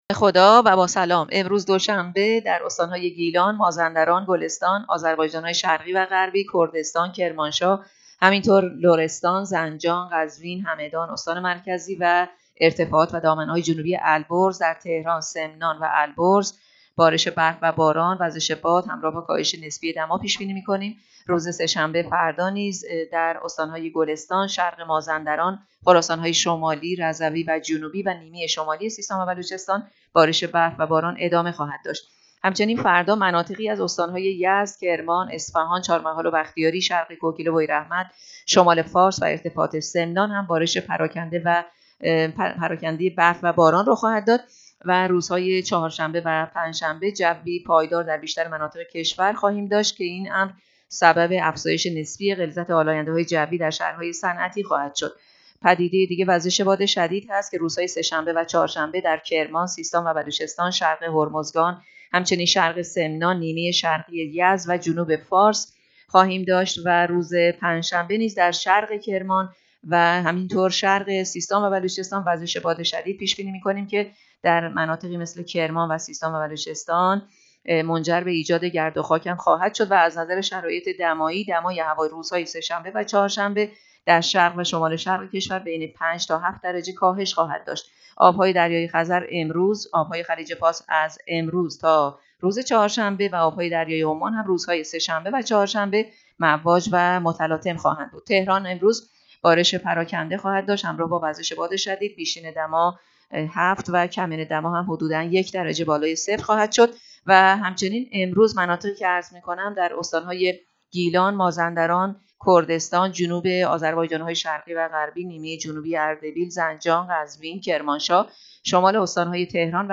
گزارش رادیو اینترنتی پایگاه‌ خبری از آخرین وضعیت آب‌وهوای ۱ بهمن؛